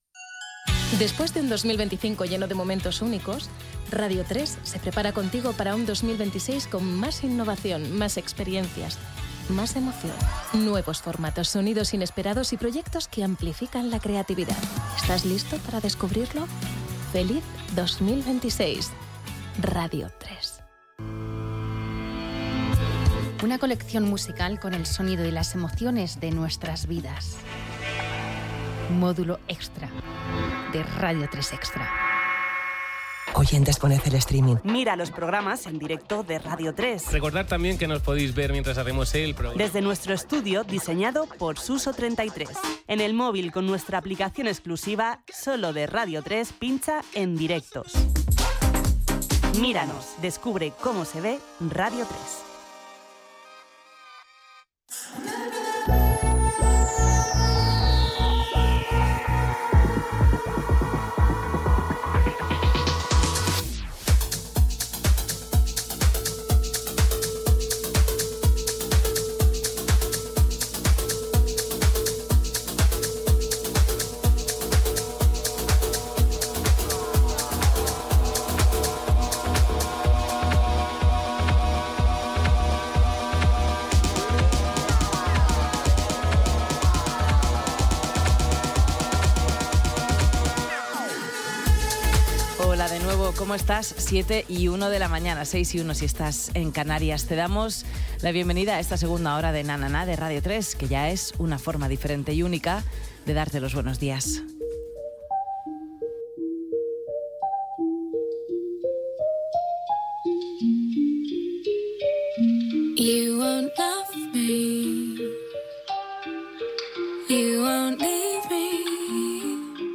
Promocions de Radio 3 al 2026, "Módulo extra" i Radio 3 en "streaming". Hora, benvinguda a la segona hora del programa, tema musical, comentari del que s'ha escoltat i presentació del següent tema
Musical